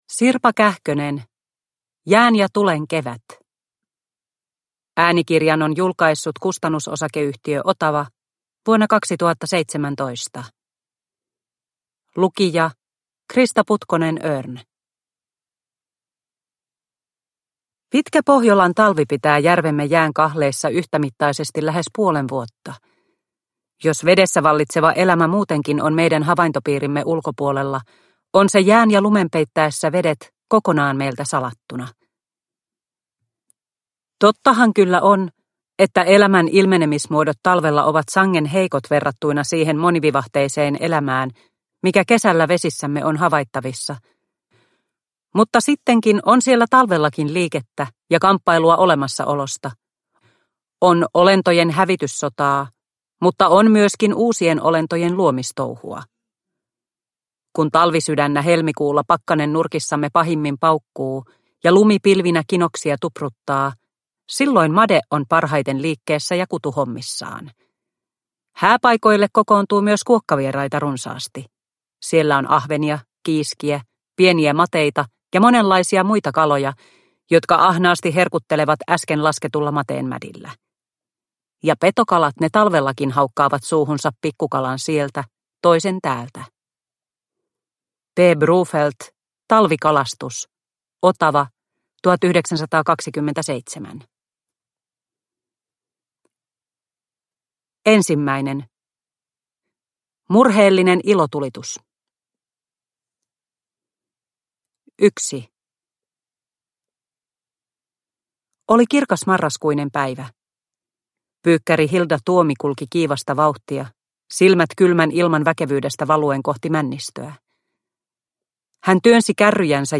Jään ja tulen kevät – Ljudbok – Laddas ner